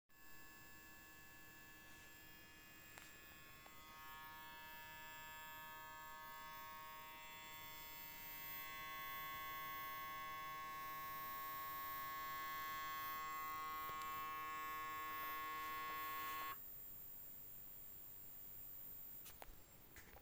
Nachdem mein Lüfter auf Grund der relativ geringen Haus-Last bei uns, nur selten anspringt finde ich das Geräusch danach aber merkwürdig.
Nachdem der Lüfter ausgeht kommt dieses komische Pfeifen/Surren, dauert etwa 15 sek. und dann ist wieder Ruhe.